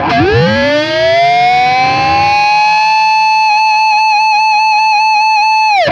DIVEBOMB21-R.wav